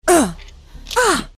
rush_sigh2.mp3